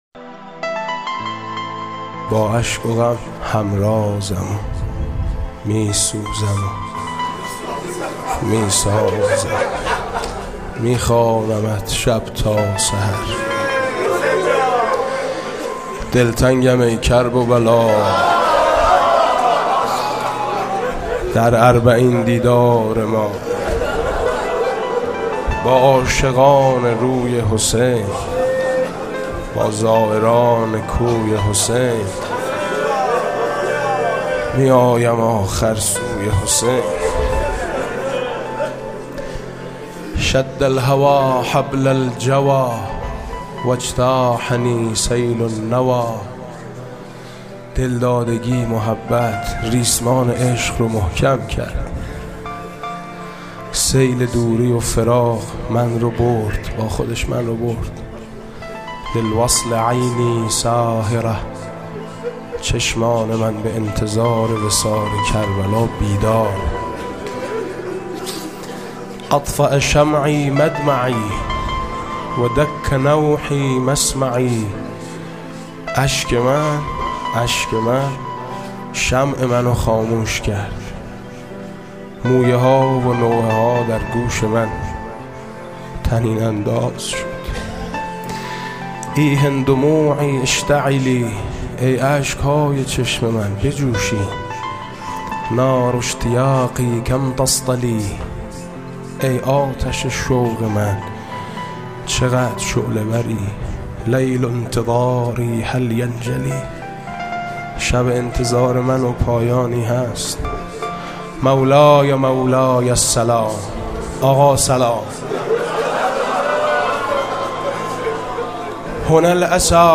شعرخوانی زیبا و احساسی با نوای حاج میثم مطیعی......دلتنگم ای کرب‌وبلا در اربعین دیدار ما